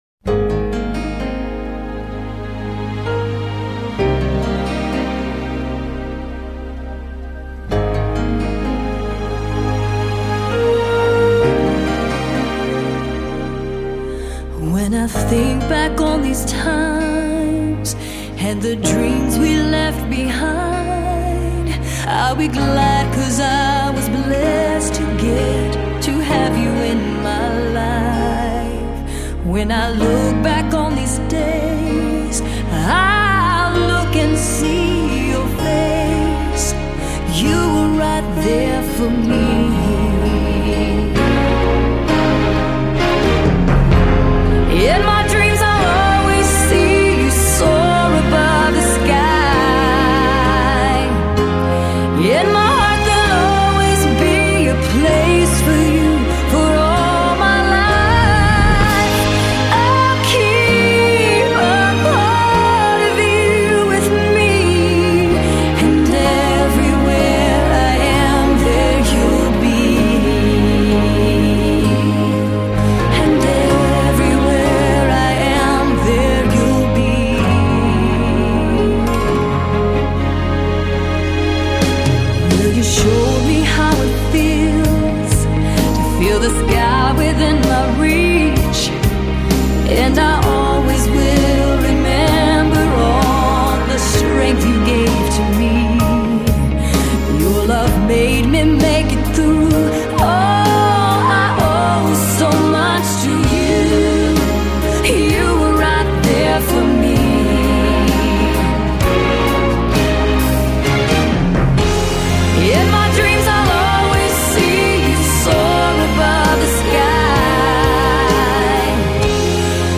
一首荡气回肠经典的歌，值得再次回味. . . . .